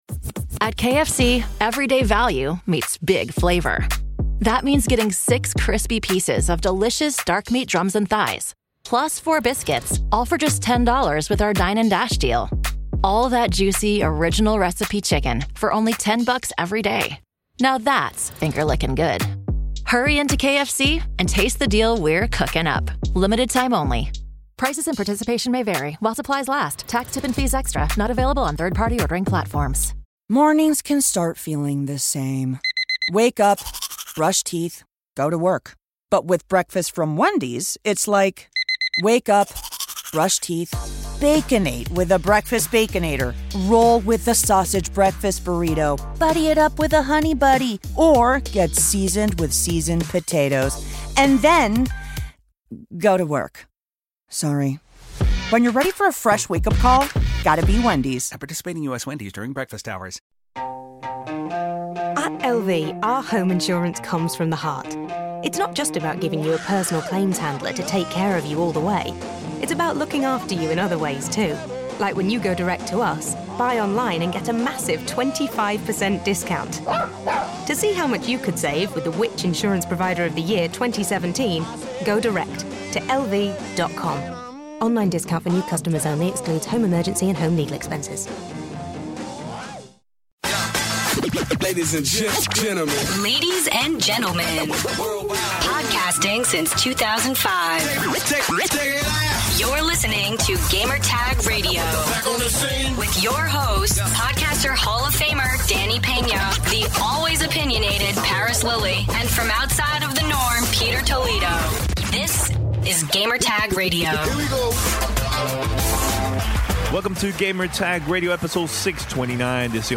Live from San Francisco, CA.